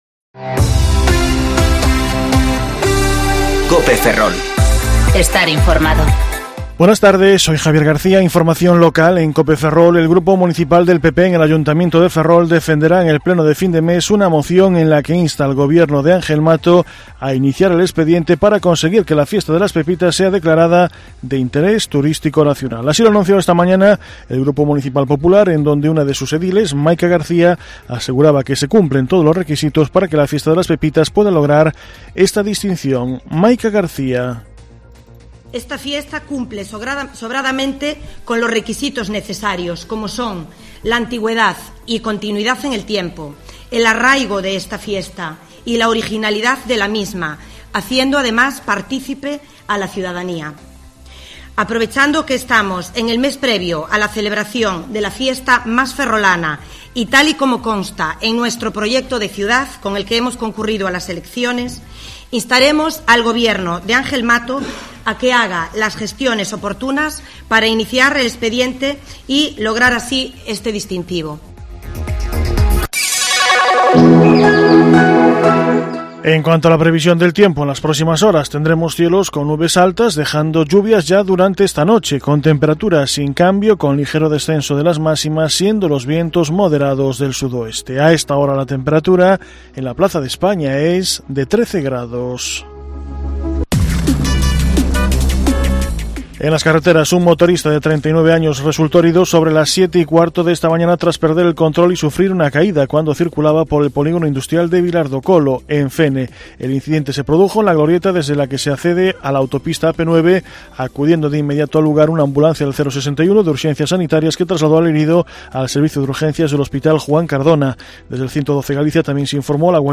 Informativo Mediodía COPE Ferrol 24/2/2020 (De 14,20 a 14,30 horas)